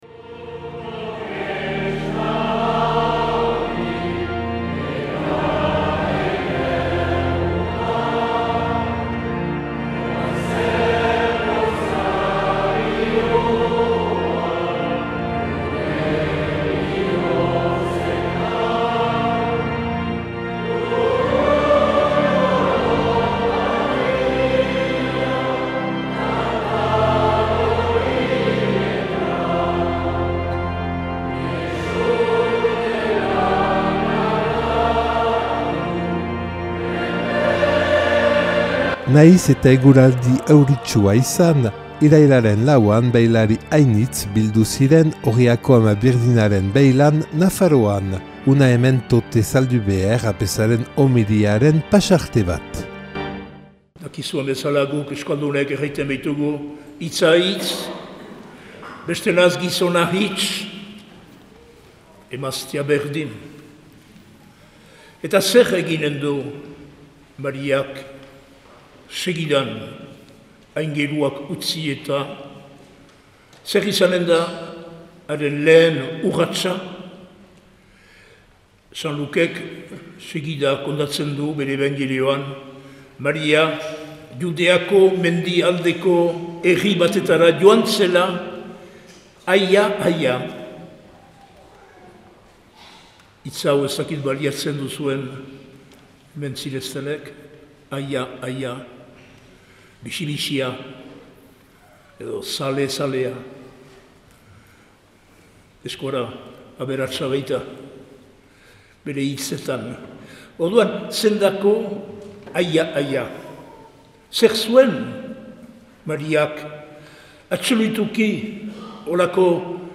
Argazkiak ikus ditzakezu eta irailaren 4ko Orriako Andredena Mariaren beilaldiaren ospakizunak entzun elizbarrutiaren webgunean hemen.